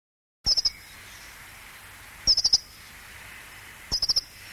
Pokrzywnica - Prunella modularis